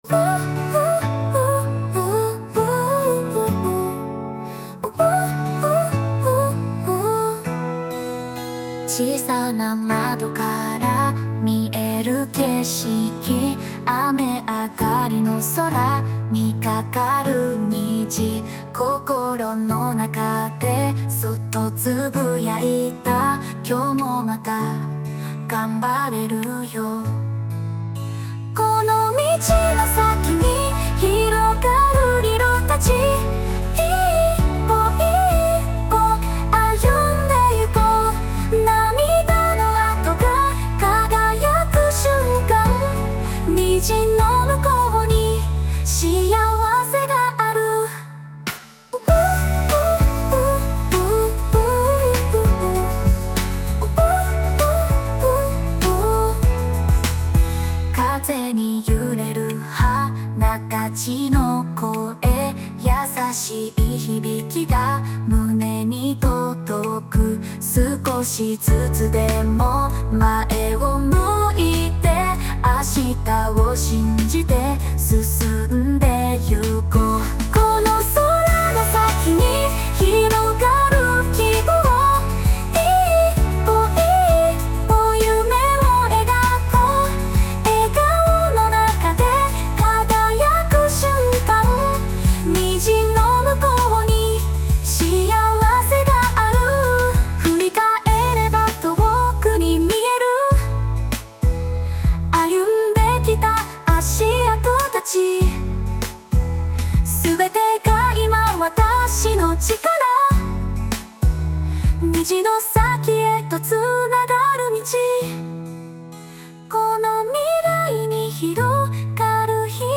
邦楽女性ボーカル著作権フリーBGM ボーカル
著作権フリーのオリジナルBGMです。
女性ボーカル（邦楽）曲です。
「虹」をテーマに、爽やかな女性ボーカルをイメージして制作しました✨